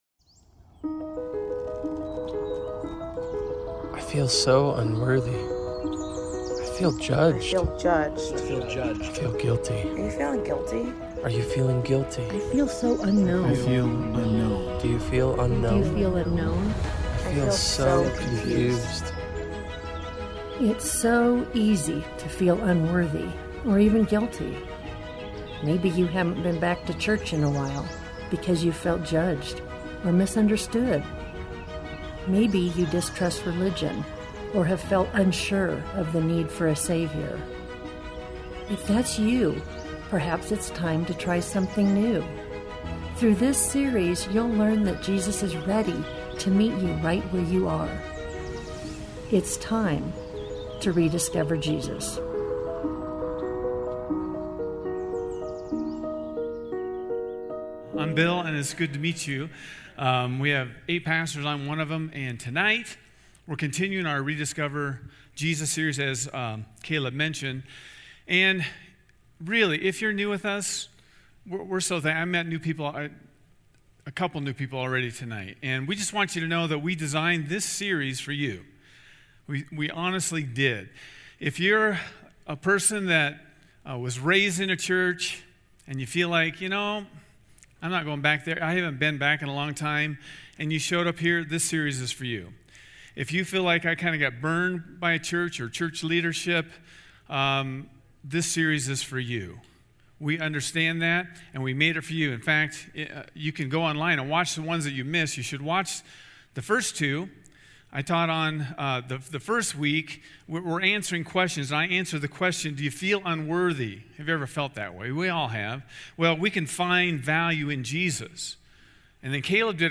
A message from the series "Rediscover Jesus." We all face times of confusion.